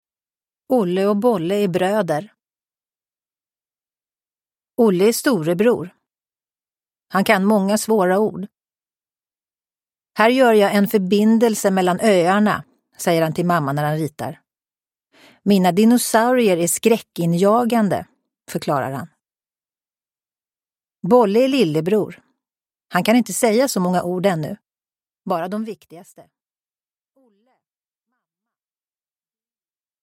Olle och Bolle handlar – Ljudbok – Laddas ner
Uppläsare: Tova Magnusson